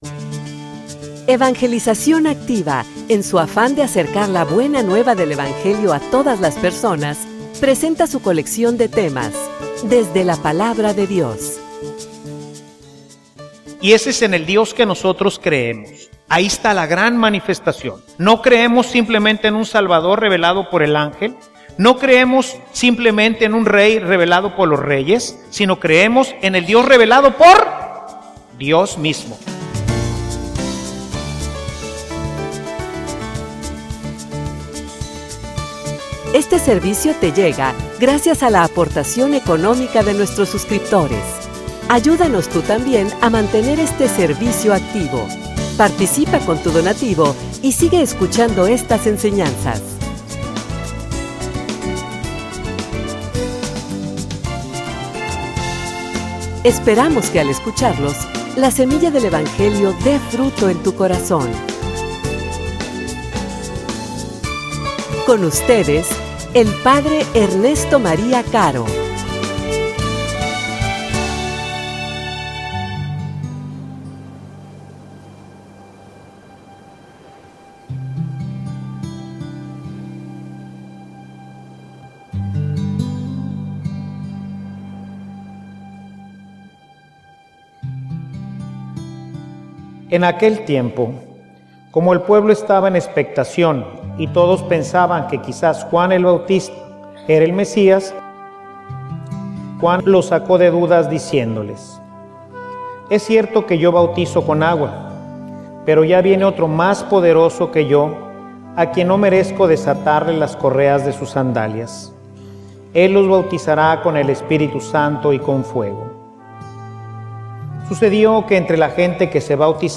homilia_Ora_y_se_abriran_los_cielos.mp3